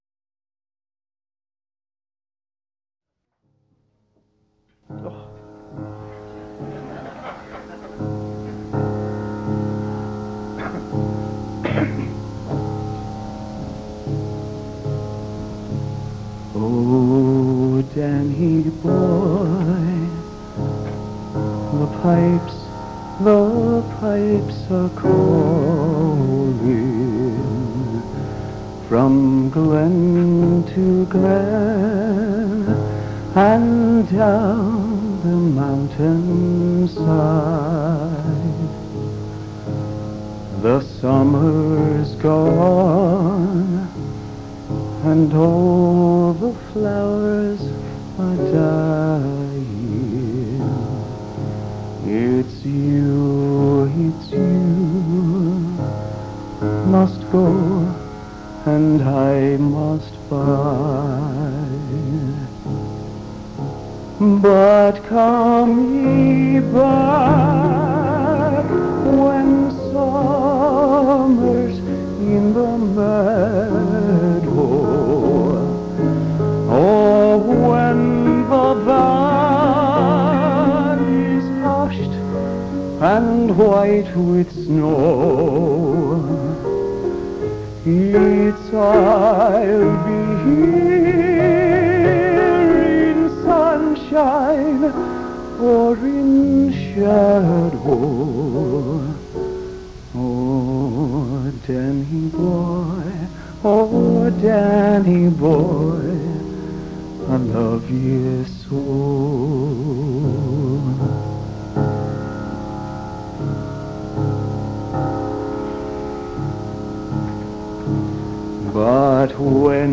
I tried to remove some of the hiss, and I’m still not happy with the muddiness of the track, but I think it’s in decent enough shape to share.